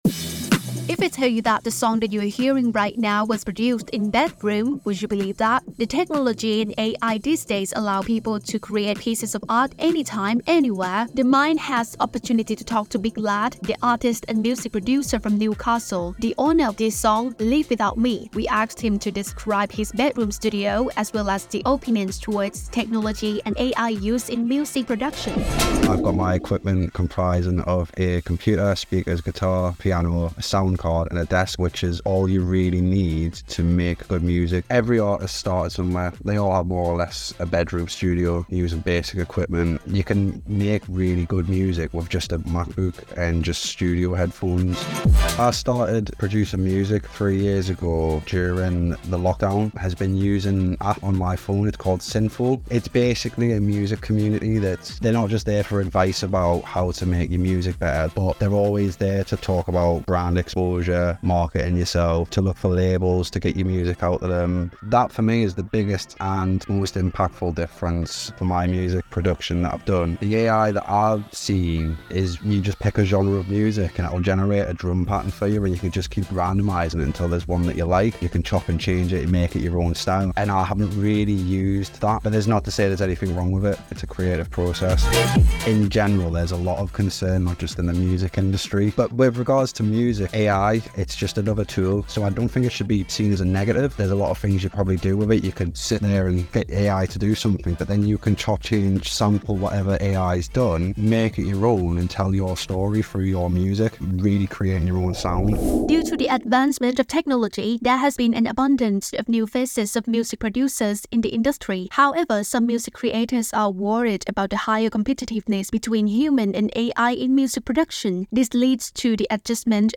News Report